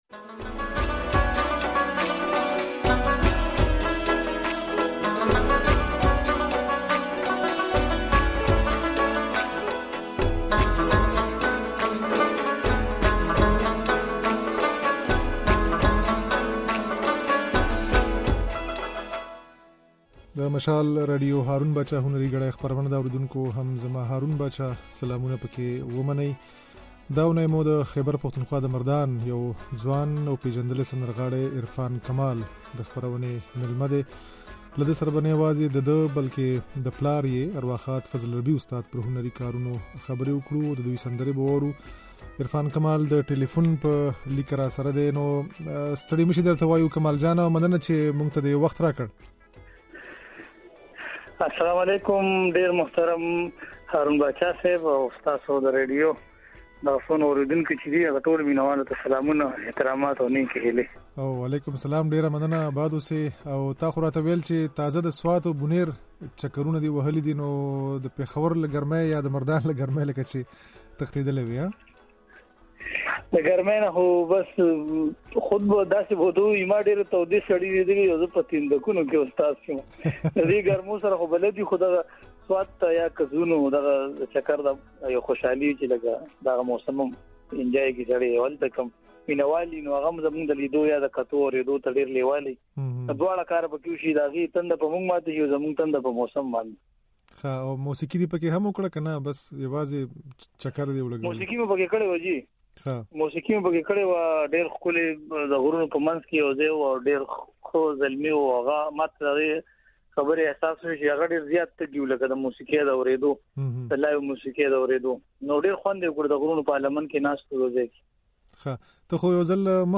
د نوموړي خبرې او ځينې سندرې يې د غږ په ځای کې اورېدای شئ.